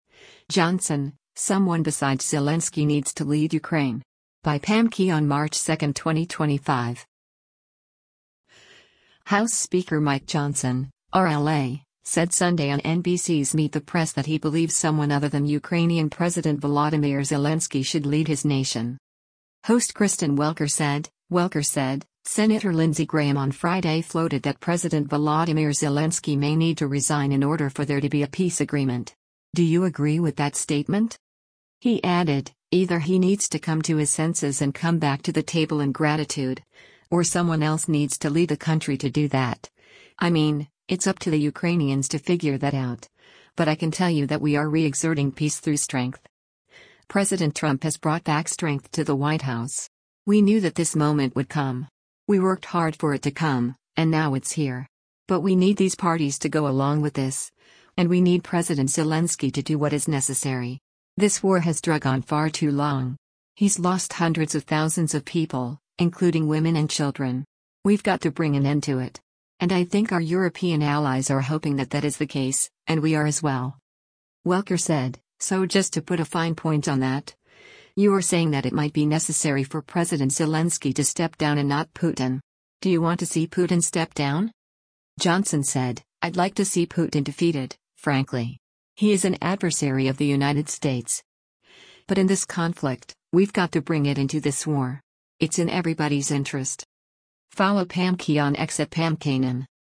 House Speaker Mike Johnson (R-LA) said Sunday on NBC’s “Meet the Press” that he believes someone other than Ukrainian President Volodymyr Zelensky should lead his nation.